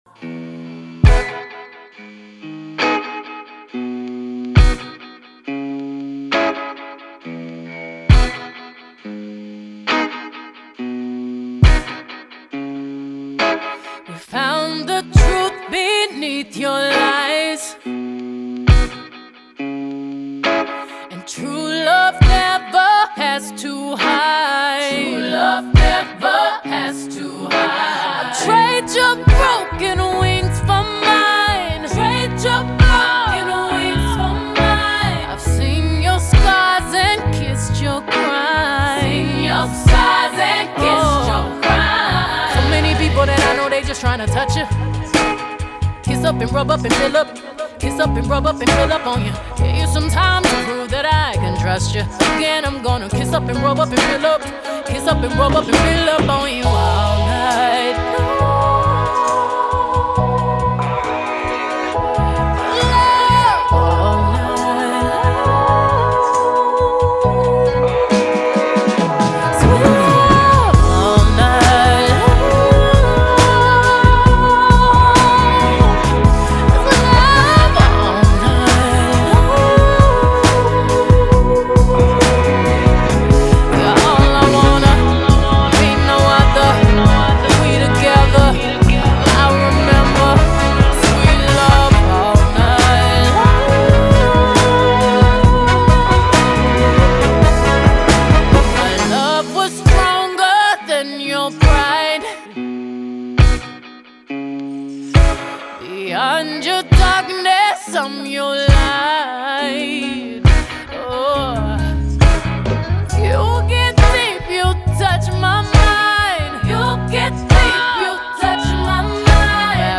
horn loop you hear on the chorus